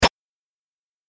دانلود آهنگ دعوا 19 از افکت صوتی انسان و موجودات زنده
جلوه های صوتی
دانلود صدای دعوای 19 از ساعد نیوز با لینک مستقیم و کیفیت بالا